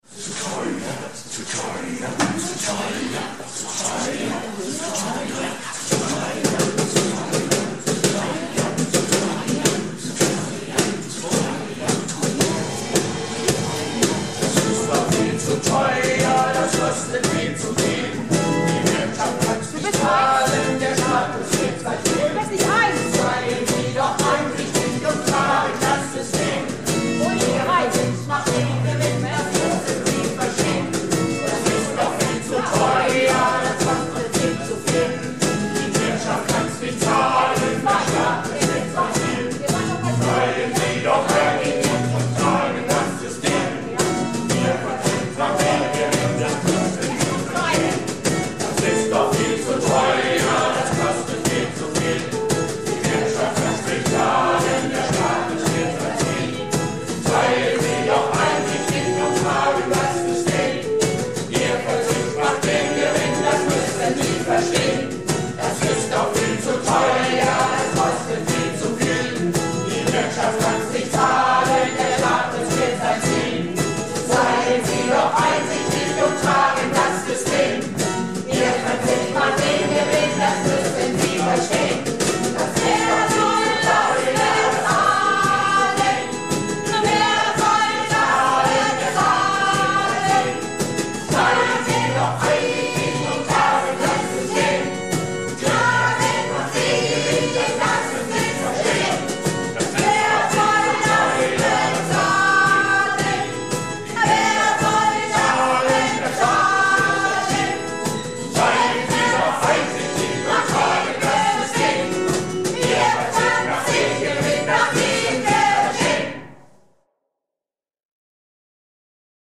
Projektchor "Keine Wahl ist keine Wahl" - Probe am 27.09.19